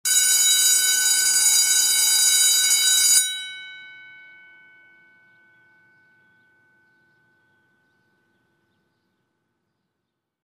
На этой странице собраны разнообразные звуки школьного звонка: от традиционных резких переливов до современных мелодичных сигналов.
Школьный звонок второй вариант